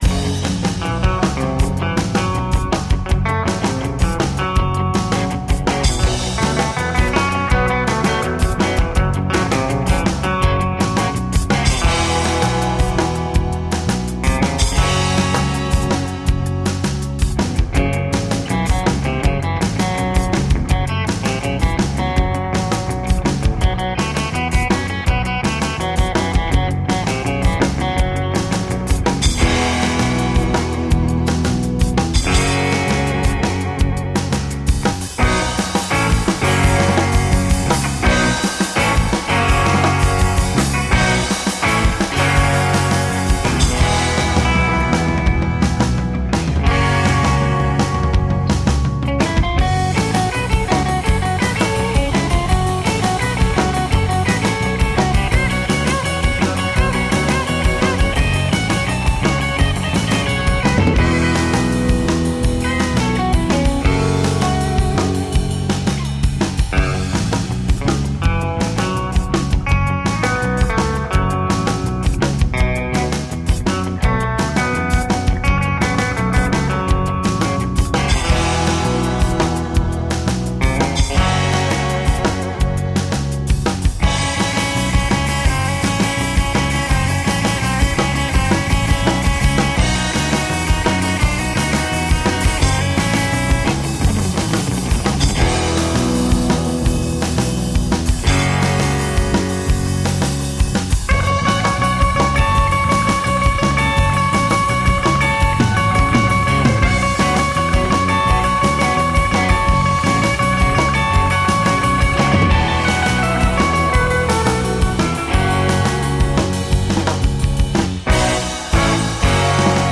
High-energy surf-inspired instrumental rock.